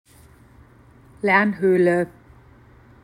Lernhöhle Pronunciation